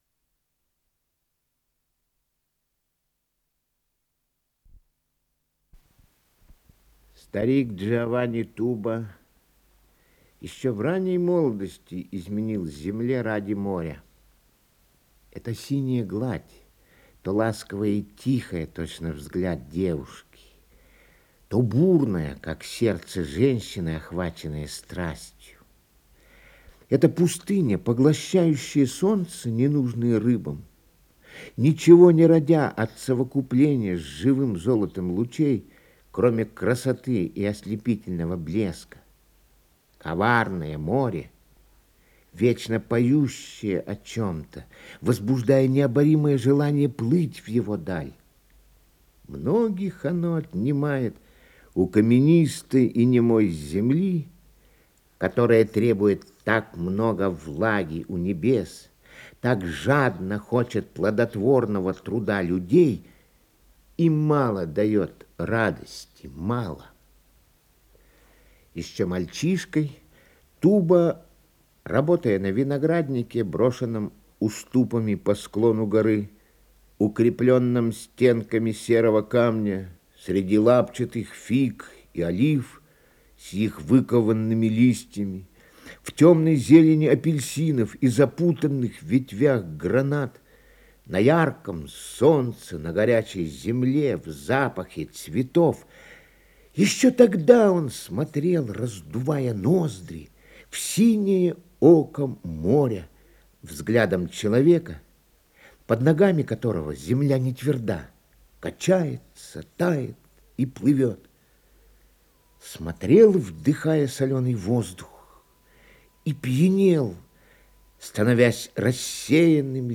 Исполнитель: Борис Чирков - чтение